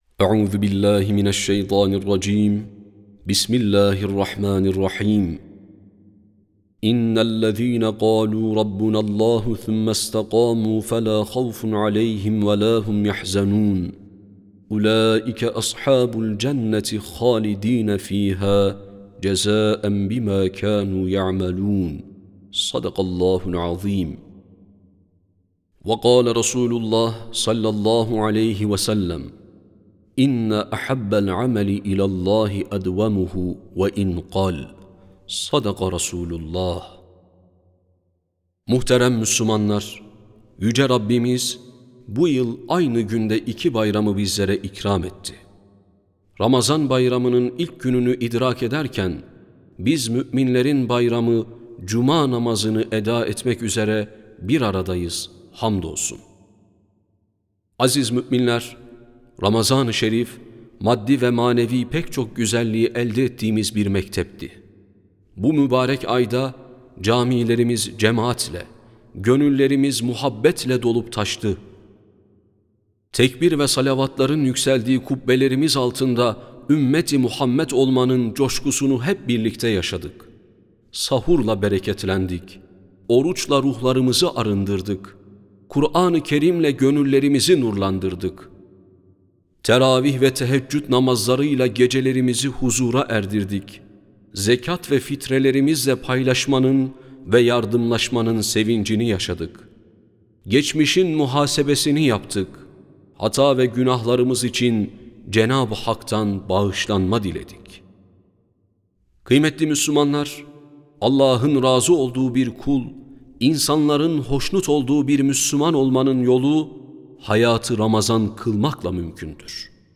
Sesli Hutbe (Hayatı Ramazan Kılmak).mp3